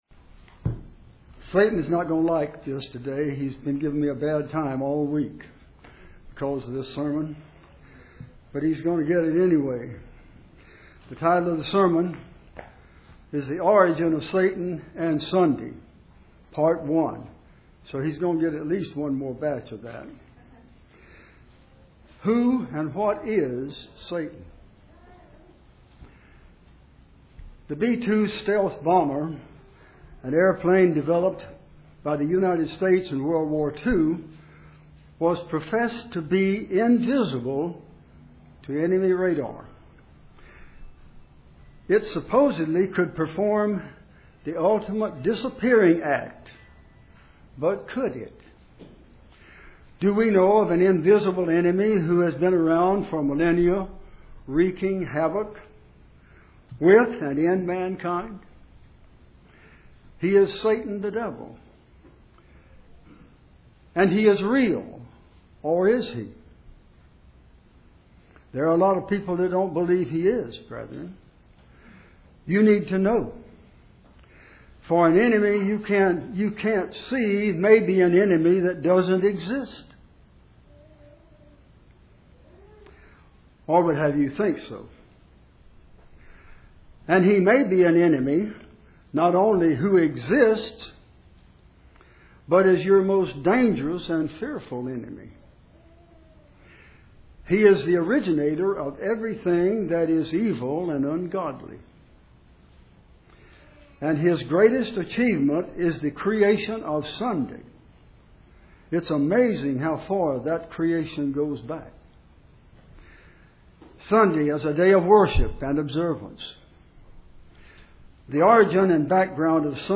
Given in Jacksonville, FL
UCG Sermon Studying the bible?